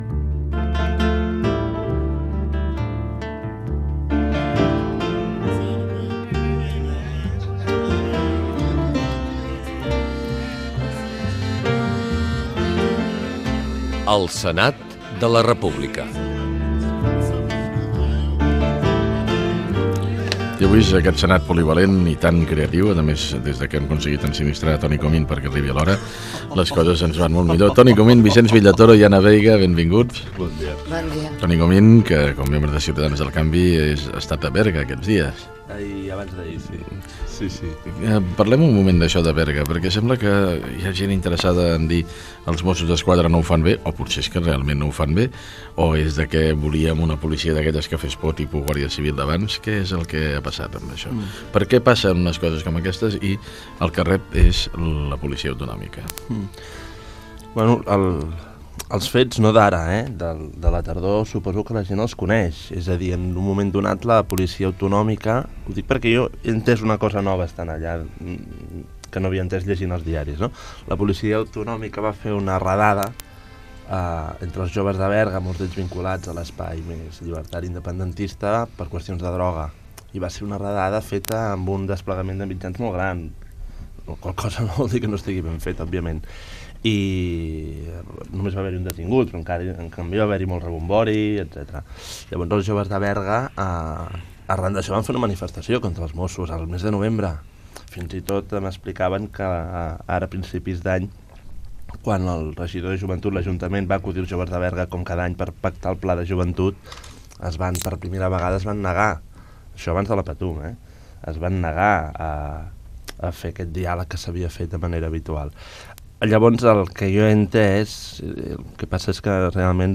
Info-entreteniment
Fragment extret de l'arxiu sonor de COM Ràdio.